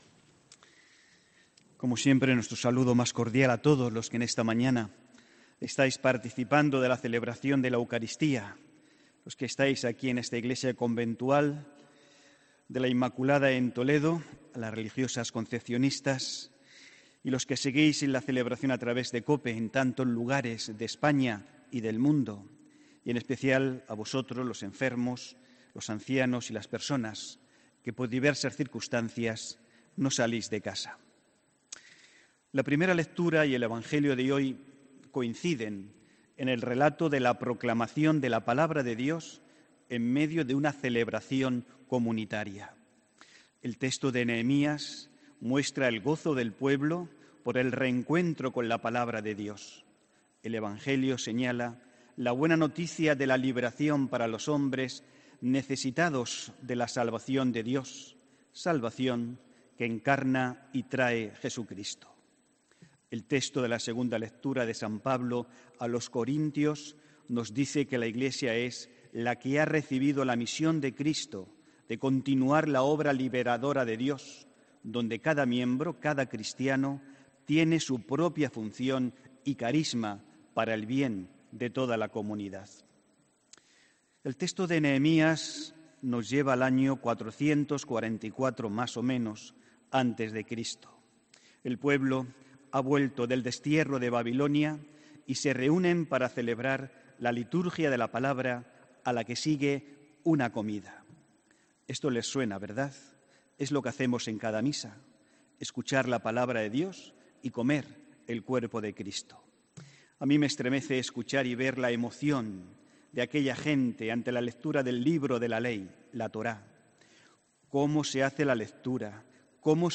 HOMILÍA 27 ENERO 2019